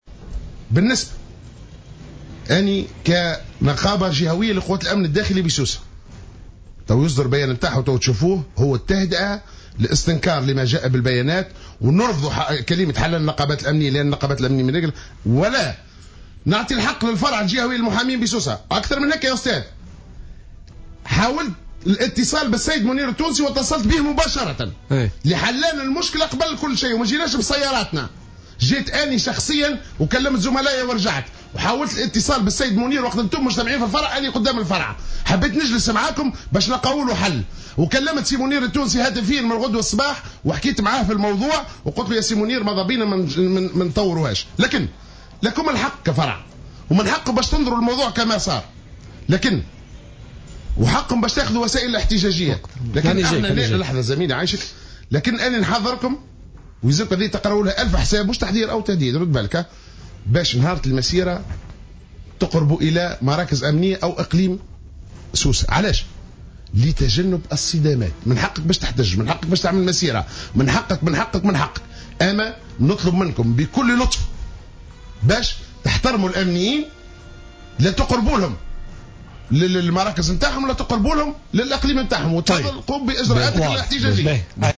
خلال حصة بوليتيكا على جوهرة أف أم اليوم الاربعاء